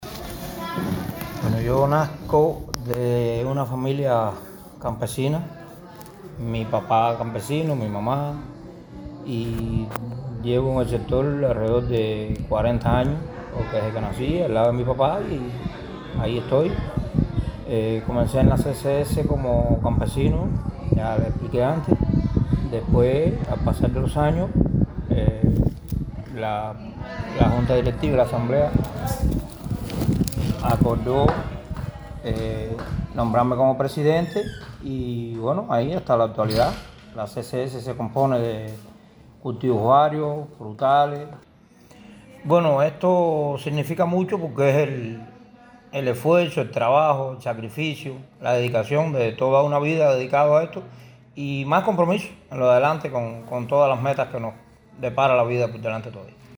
En vísperas del 65 aniversario de la promulgación de la Primera Ley de Reforma Agraria se impusieron condecoraciones y el sello especial a cooperativistas, campesinos, cuadros destacados y trabajadores con resultados en acto solemne en el Castillo de San Severino, en la ciudad de Matanzas.